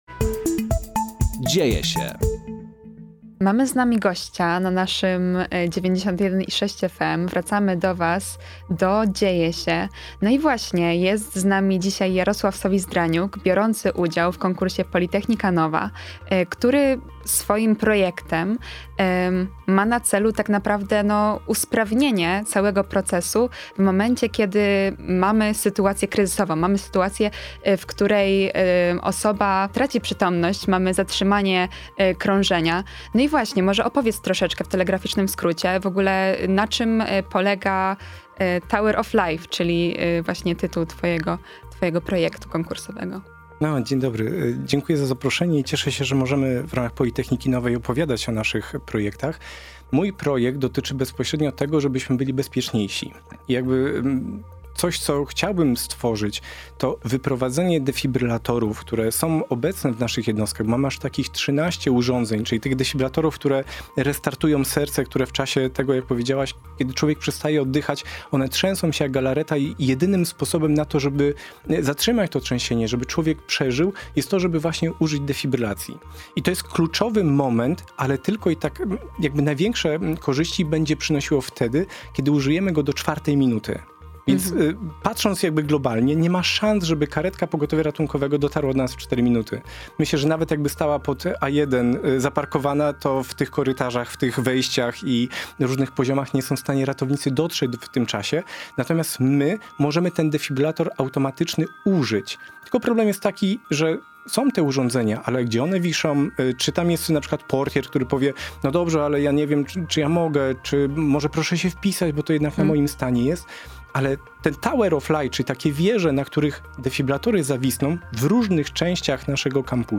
POLYTECHNICA NOVA – wywiady z laureatami pierwszego etapu konkursu
Wraz z nim ruszył nasz cykl rozmów z przedstawicielami zakwalifikowanych wniosków na antenie Akademickiego Radia Luz.